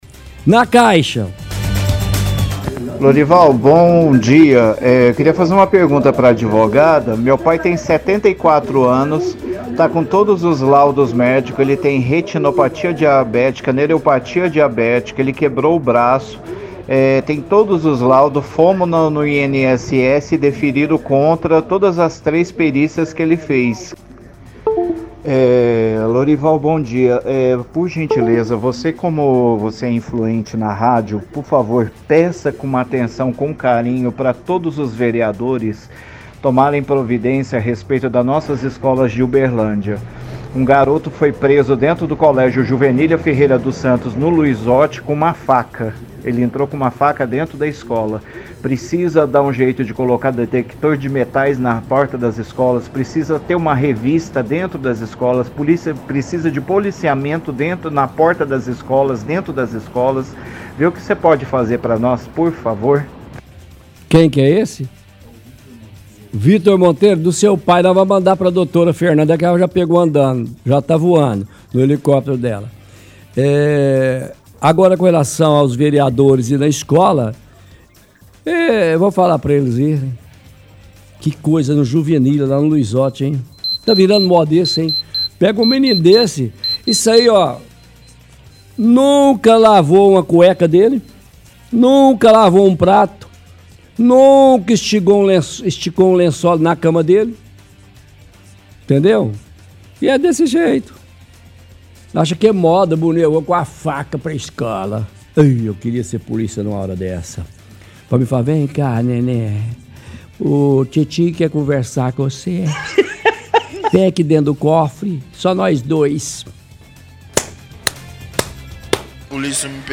– Ouvinte pede que os vereadores tenham atenção com a segurança nas escolas da cidade.